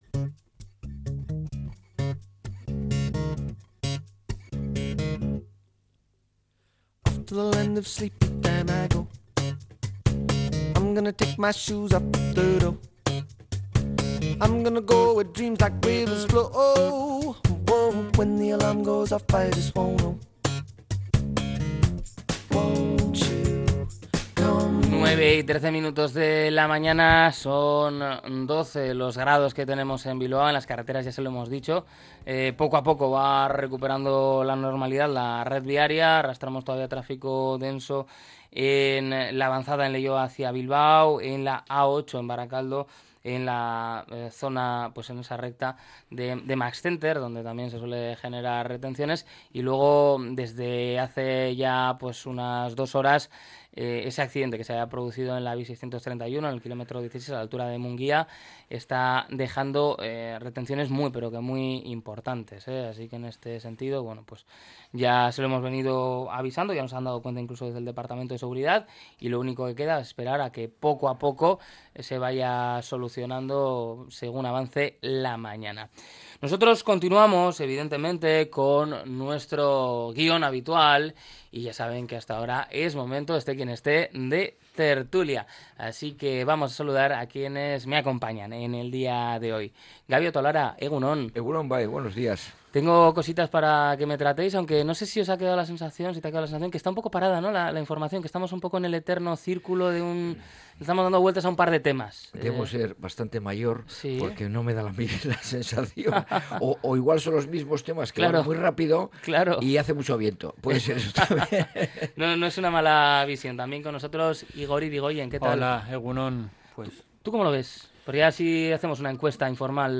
La tertulia 10-03-25.